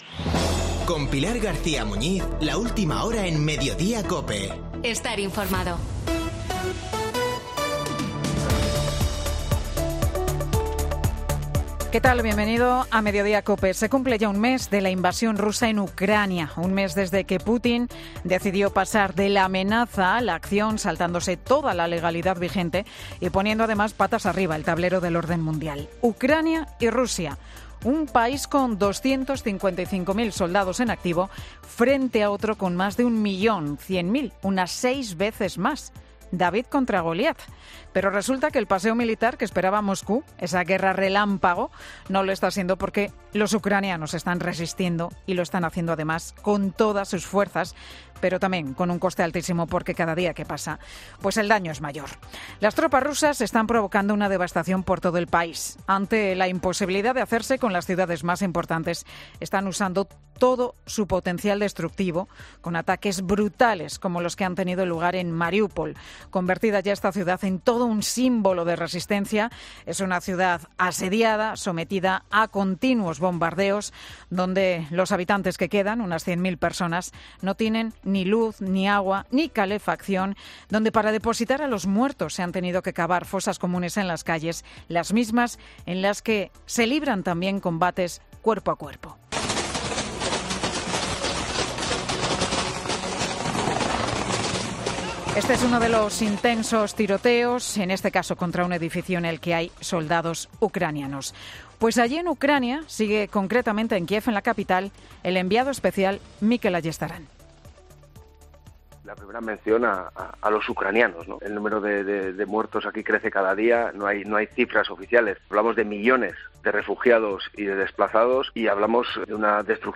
El monólogo de Pilar García Muñiz, en MediodíaCOPE